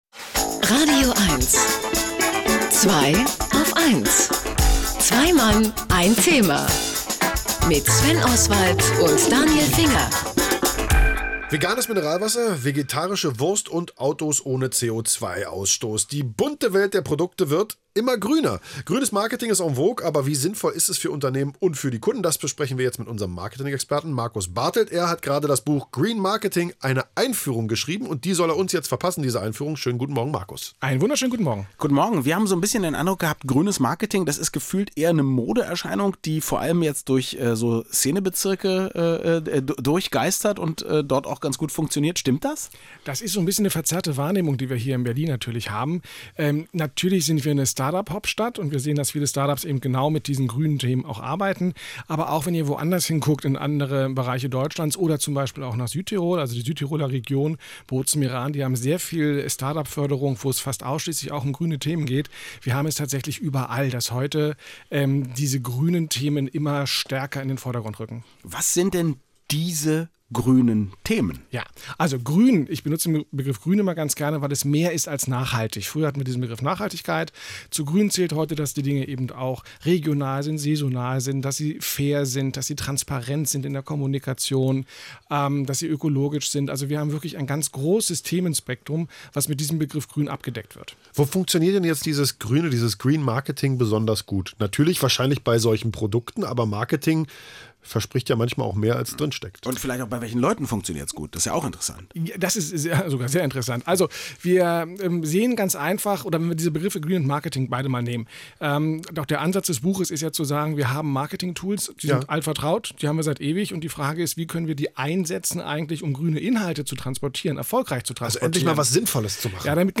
Auch die Macher von „Zweiaufeins“ sind aus ihrem Winterschlaf erwacht und luden mich zum Thema „grün“ ins radioeins-Studio: